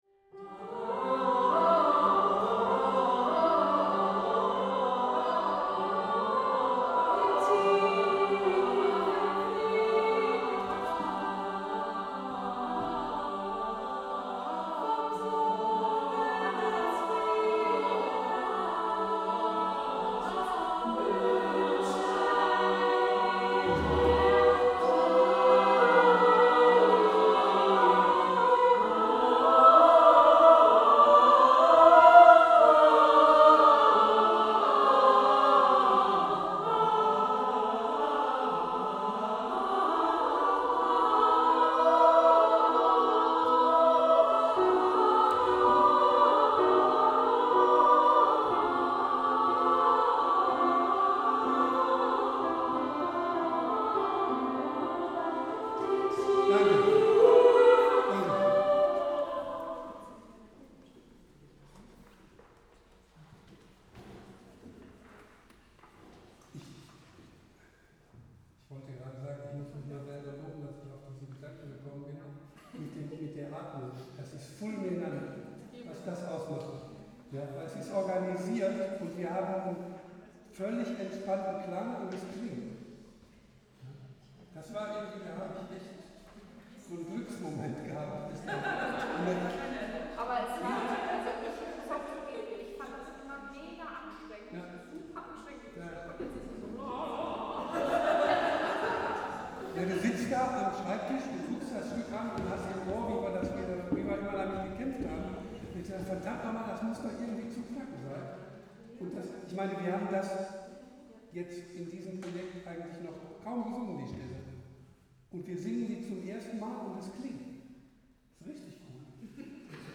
Den tiefen Frieden | 2026-03-08 - Konzertchor Sång
Den-tiefen-Frieden-Probe.mp3